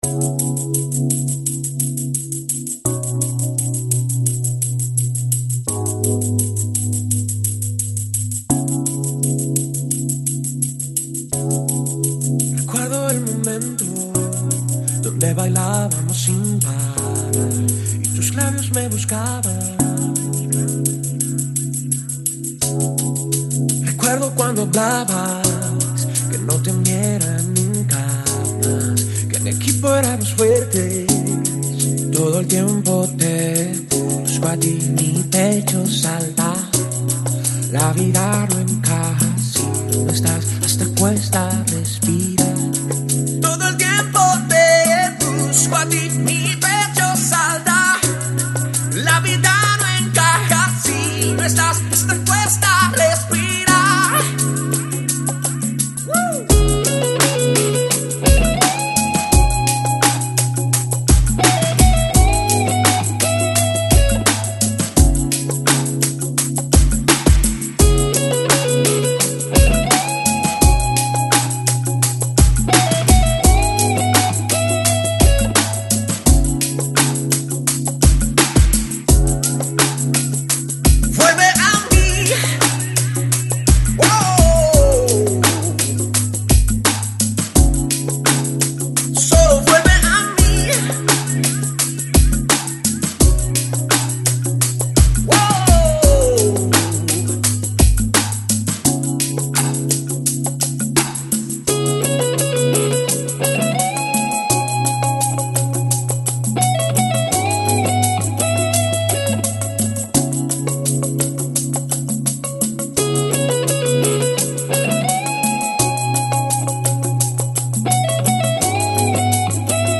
Lounge, Chill House, Downtempo, Electronic